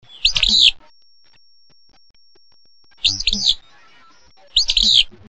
b. Song,
fitz'bew, a whistle superimposed on a buzz, 16-28/min (R).
Distinctive song, a sneezy fitz-bew; often sings during spring migration (N).